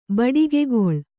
♪ baḍigegoḷ